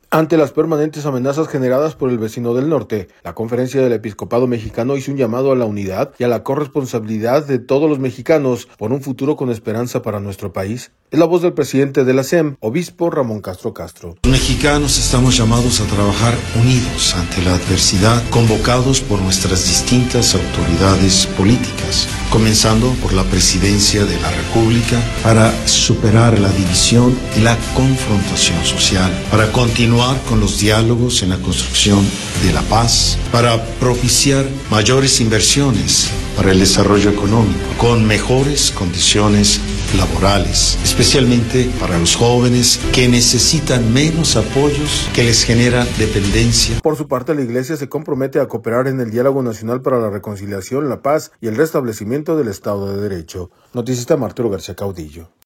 Ante las permanentes amenazas generadas por el vecino del norte, la Conferencia del Episcopado Mexicano hizo un llamado a la unidad y a la corresponsabilidad de los mexicanos por un futuro con esperanza para nuestro país. Es la voz del presidente de la CEM, obispo Ramón Castro Castro.